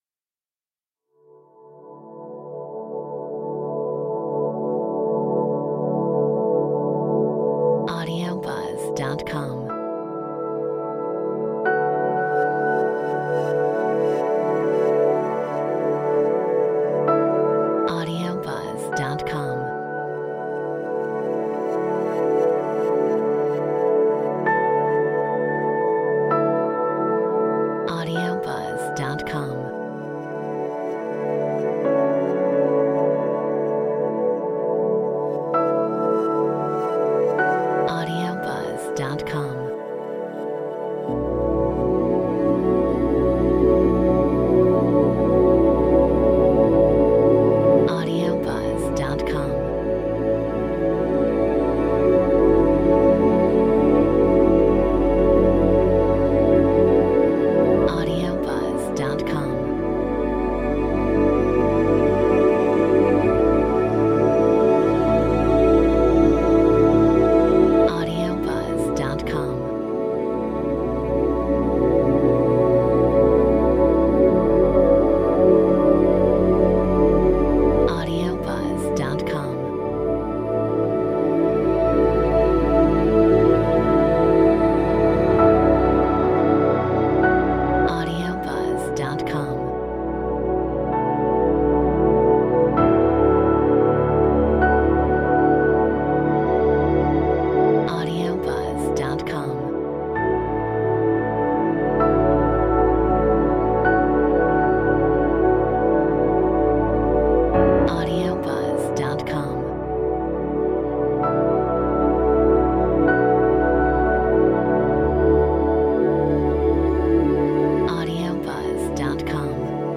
Metronome 65